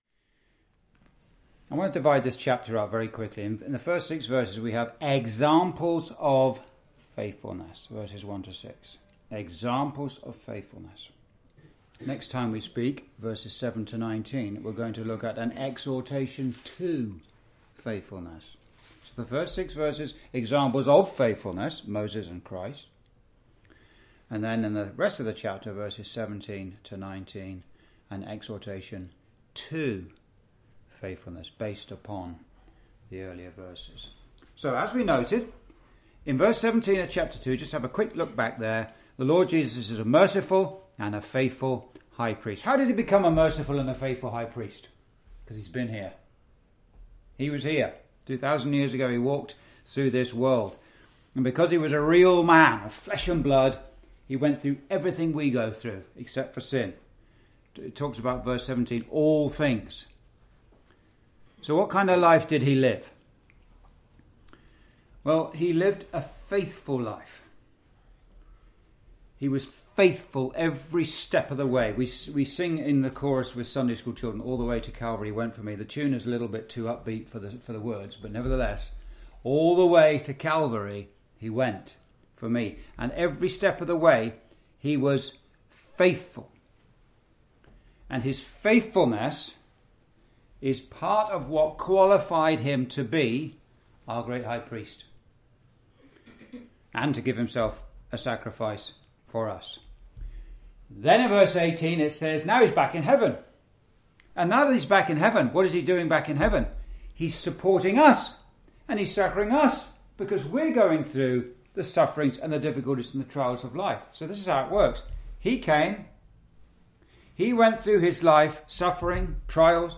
Verse by Verse Exposition